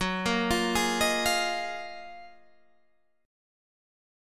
F#M13 chord